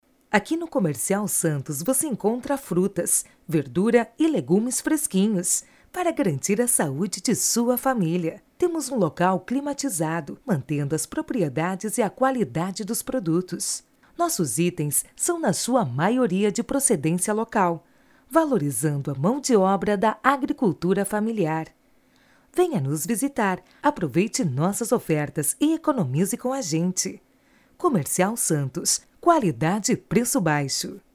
padrão: